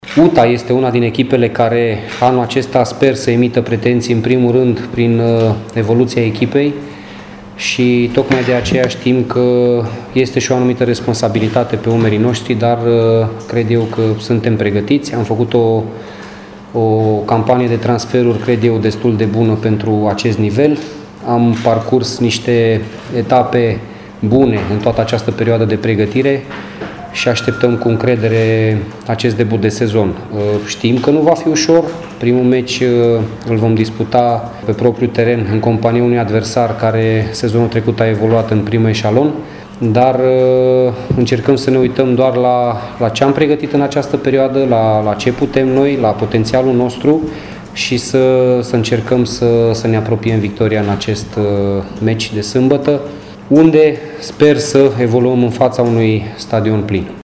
UTA s-a prezentat în fața propriilor suporteri, dar și-a expus și obiectivele pentru sezonul oficial, cu ocazia conferinței de presă dinaintea etapei inaugurale.